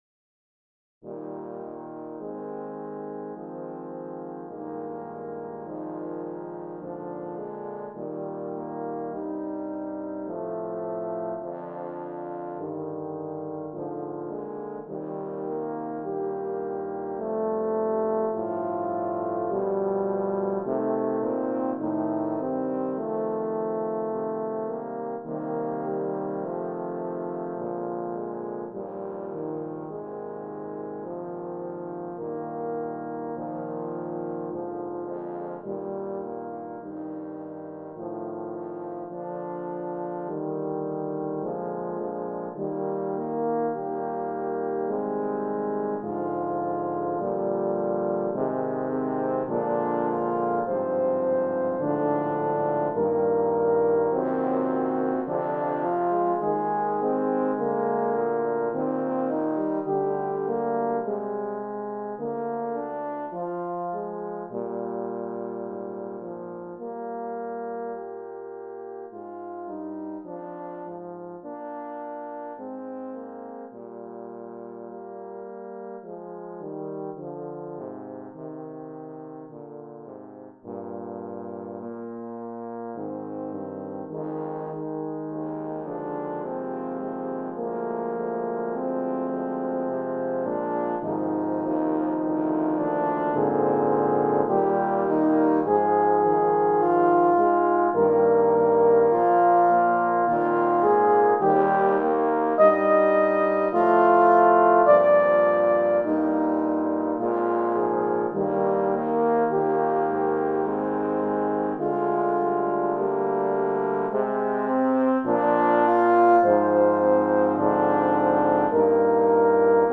Voicing: French Horn Octet